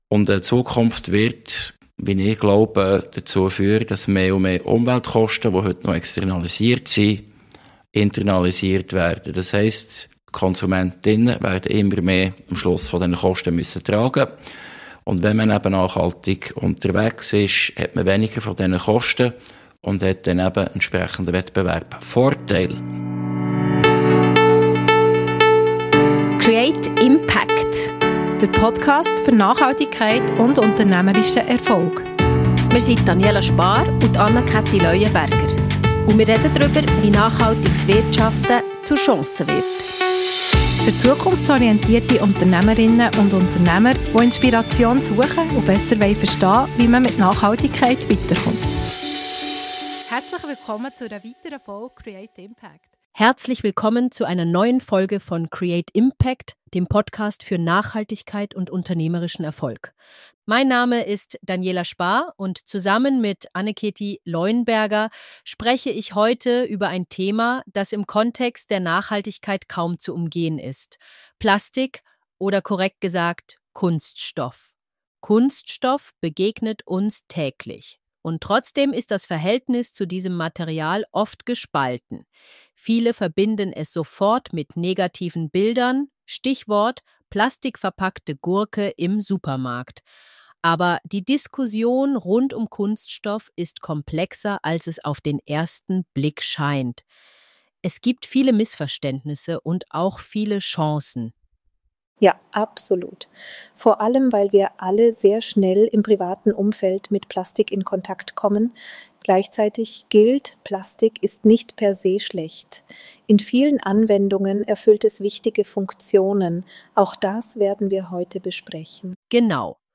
Deutsche KI-Übersetzung: Kunststoff-Recycling: Vom Teil des Problems zum Treiber der Lösung ~ create impACT Podcast
Disclaimer: Die Folge wurde mit KI von schweizerdeutsch ins hochdeutsche übersetzt.